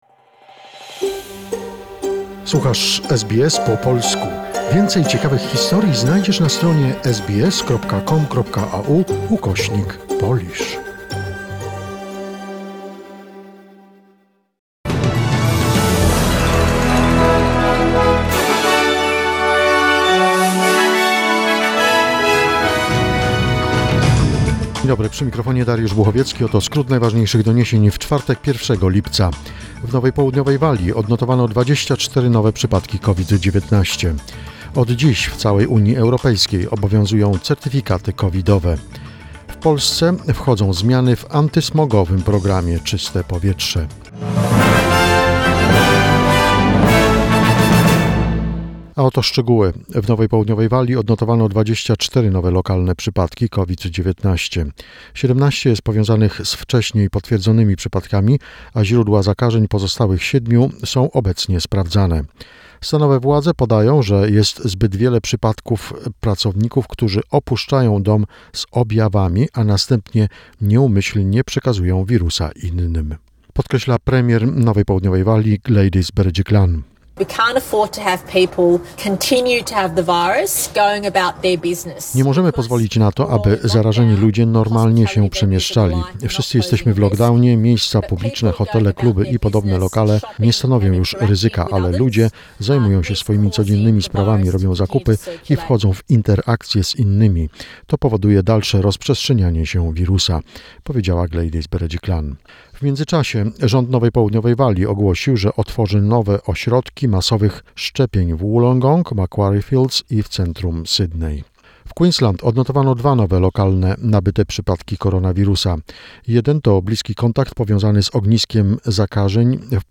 SBS News Flash in Polish, 1 July 2021